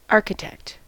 architect: Wikimedia Commons US English Pronunciations
En-us-architect.WAV